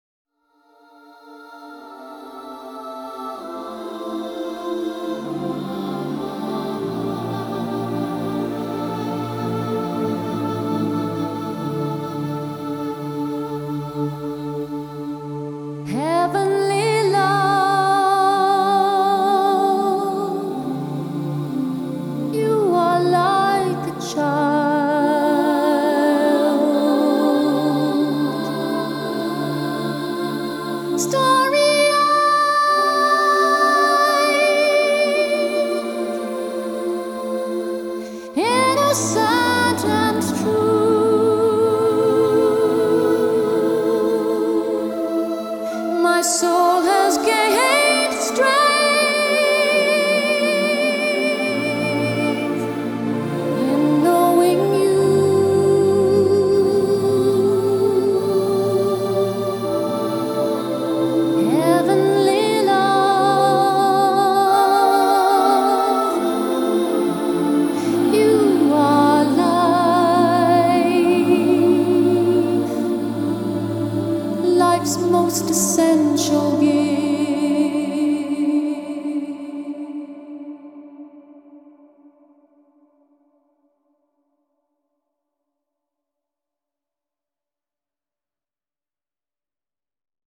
音乐类别：凯尔特元素